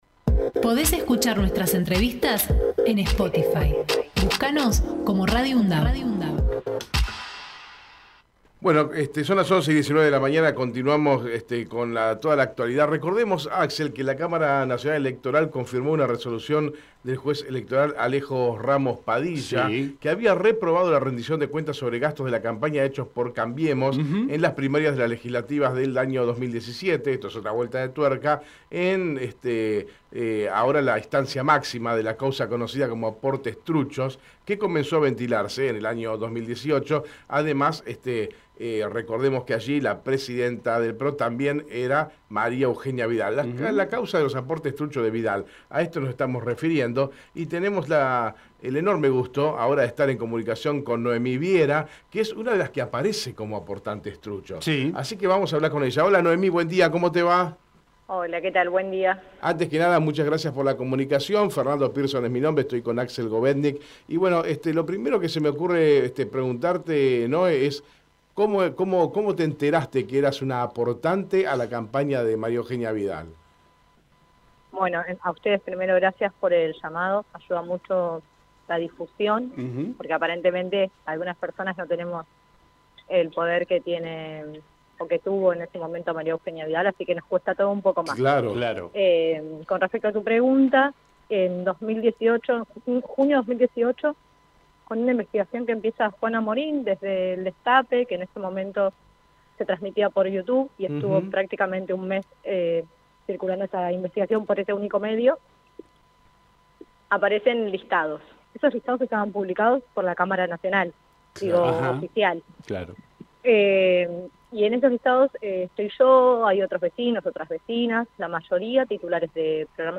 Compartimos la entrevista realizada en Hacemos PyE